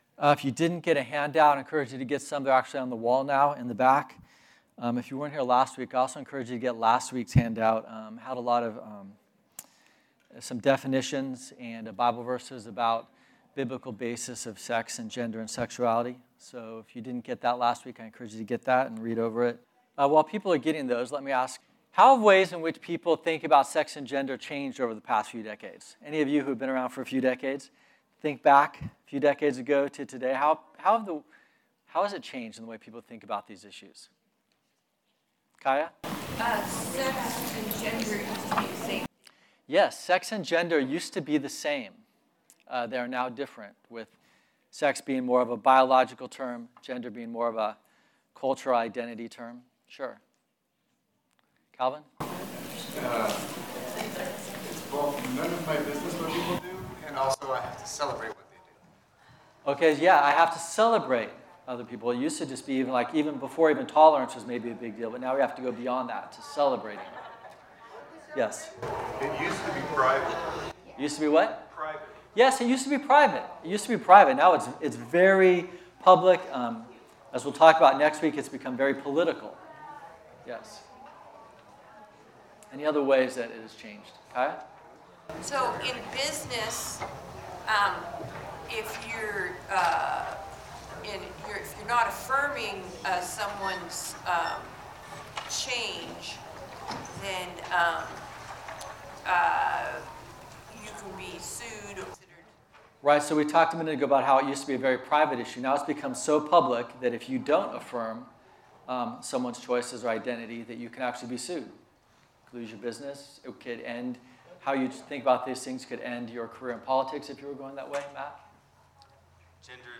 Type: Sunday School